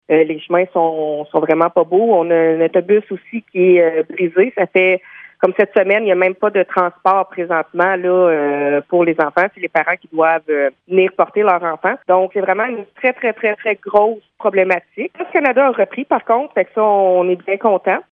Voici les propos de la mairesse de Montcerf-Lytton, Véronique Danis, recueillis la semaine dernière lorsque le bris était toujours en cours :